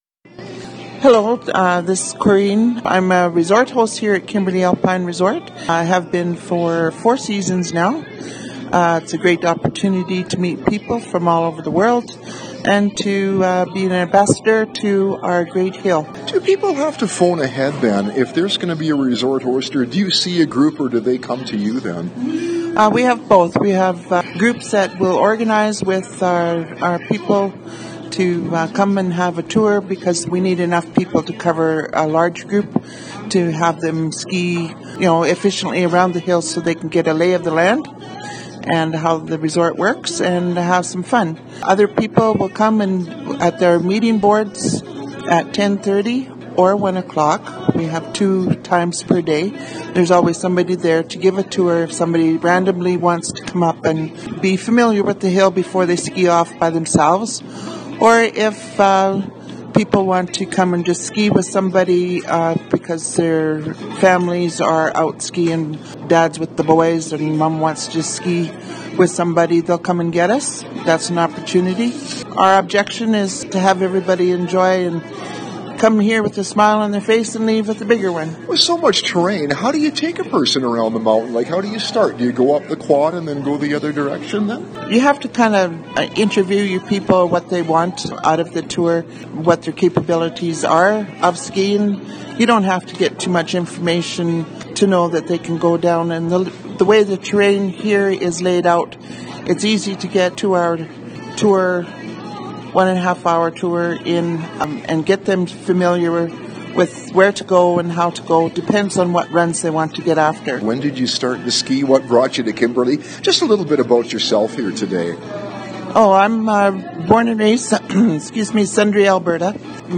Kimberley Alpine Resort on a blue sky day in February
Interviews with Mountain volunteers and employees below the